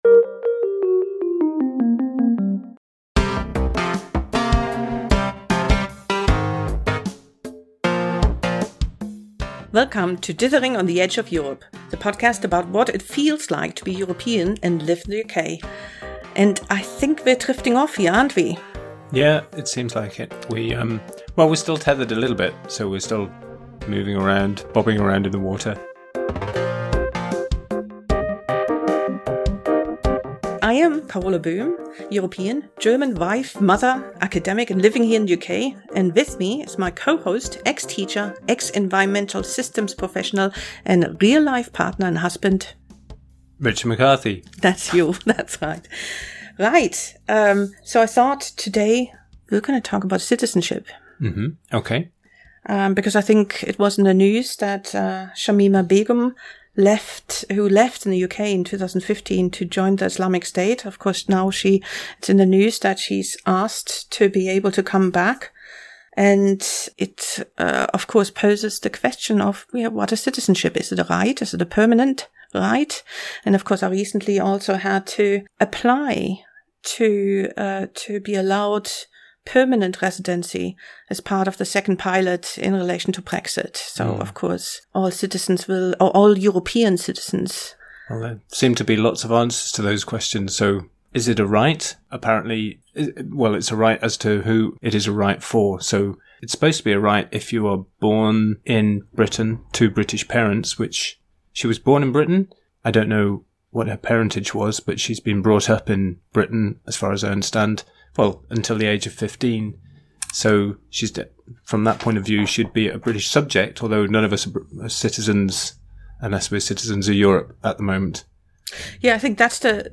We recorded this episode back in March, but it is still timely.